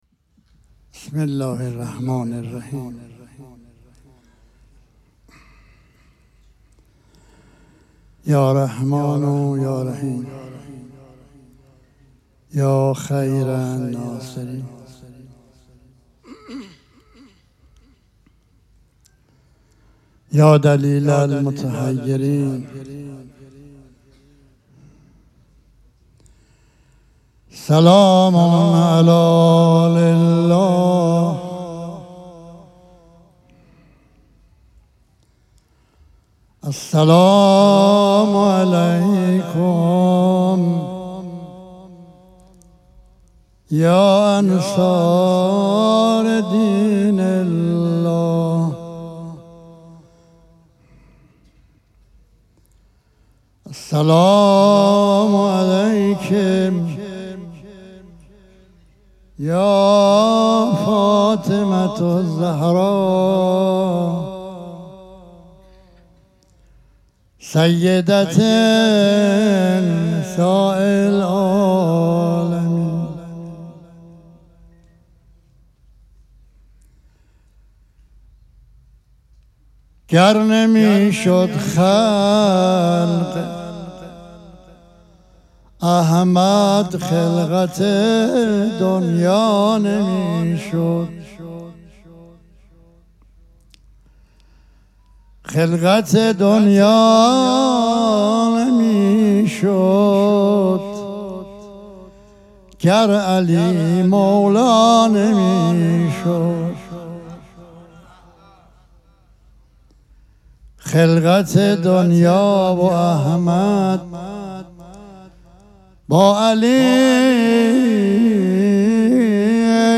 شب پنجم مراسم عزاداری دهه دوم فاطمیه ۱۴۴۶
پیش منبر